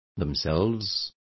Complete with pronunciation of the translation of themselves.